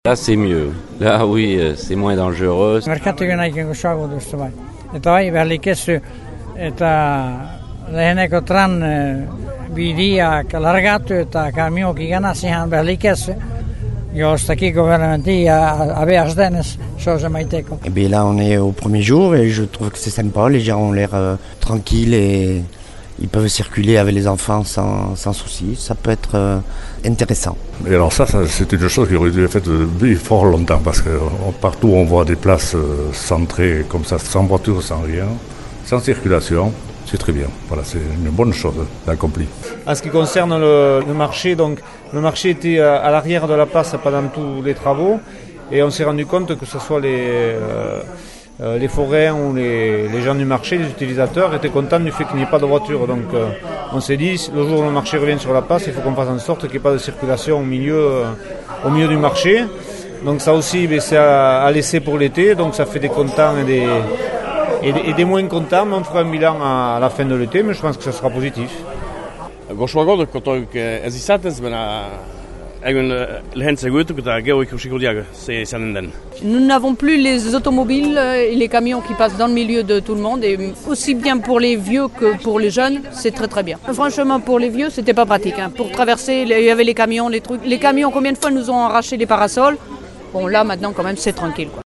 Entzün plazan ibili diren jente, merkazale, saltzale eta komerxant zonbaiten lelükotarzüna :